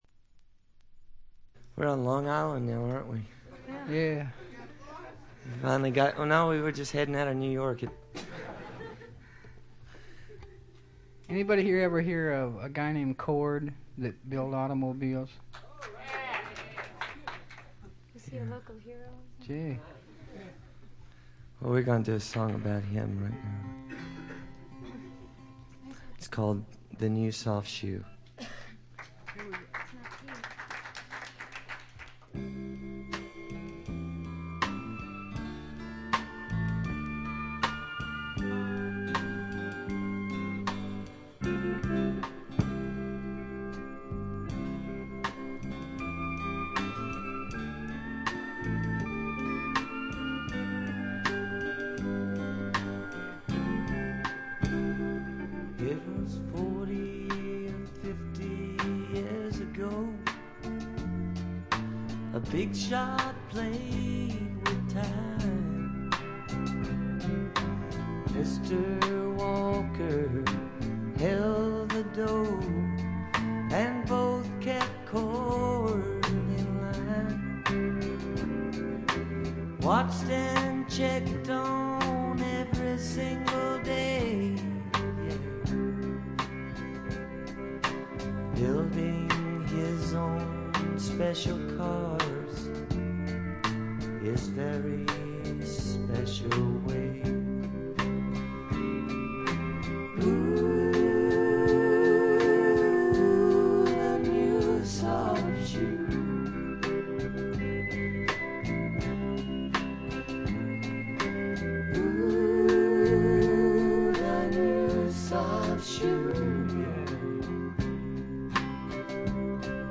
ホーム > レコード：カントリーロック
ほとんどノイズ感無し。
試聴曲は現品からの取り込み音源です。